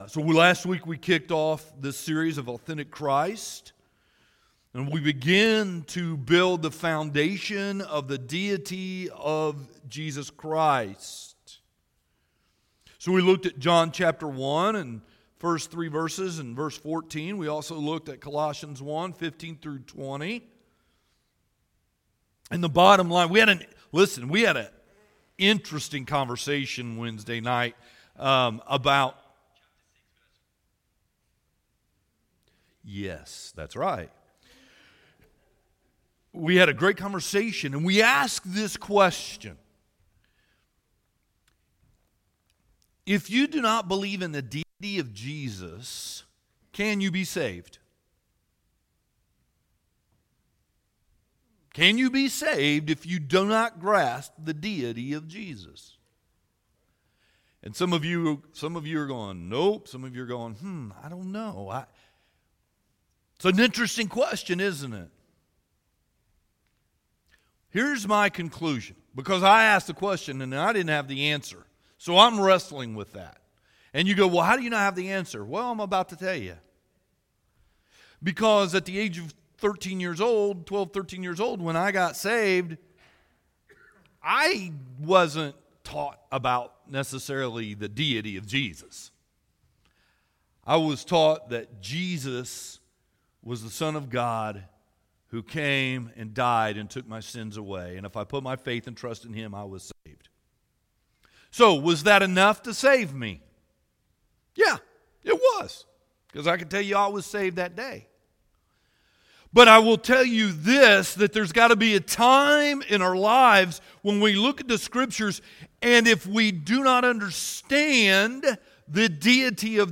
Sermons | Old Town Hill Baptist Church